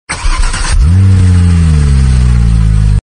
ad_car_qi_dong.MP3